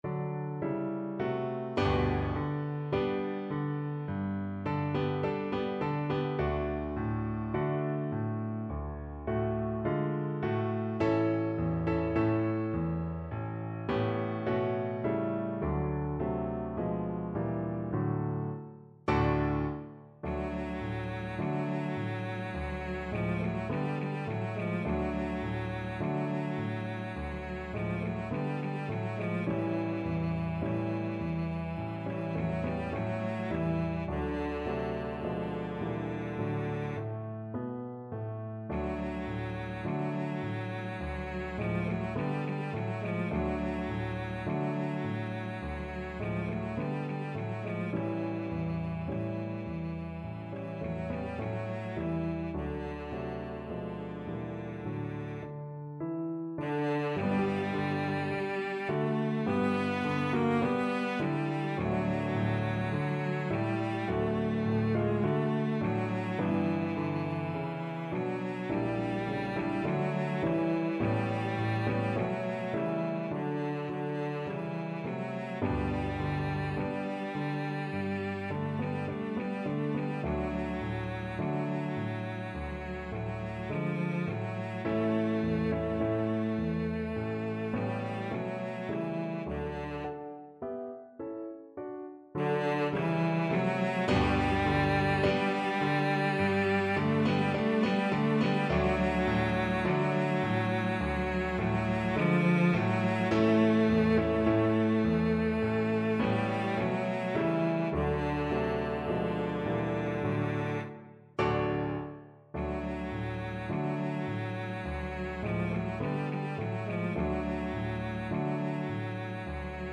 Andantino =c.52 (View more music marked Andantino)
2/4 (View more 2/4 Music)
Classical (View more Classical Cello Music)